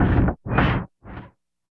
81 NOISE 2-L.wav